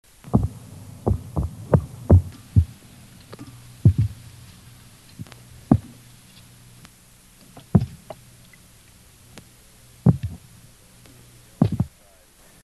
Sounds Made by Lutjanus synagris
Sound produced yes, active sound production
Type of sound produced knocks
Sound production organ swim bladder
Behavioural context only under duress (electric stimulation)
Remark recording amplified by 3 dB